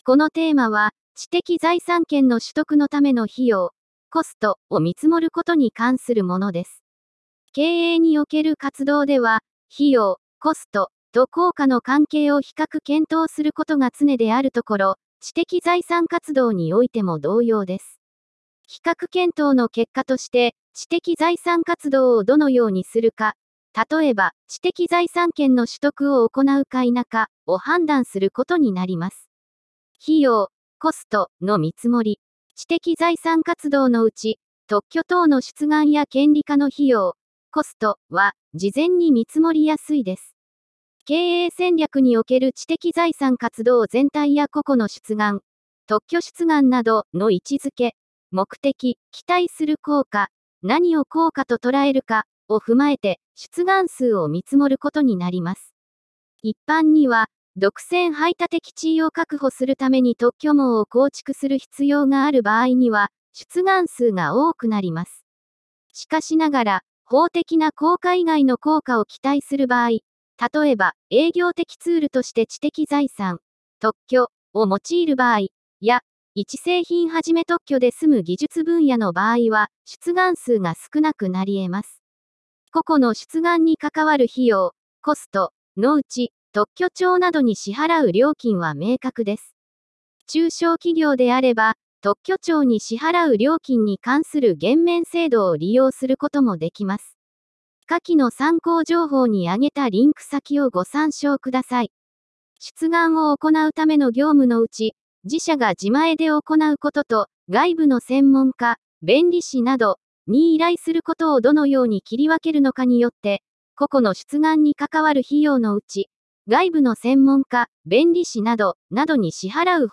テーマの説明音声データ＞＞（改訂部分が一部反映されていません）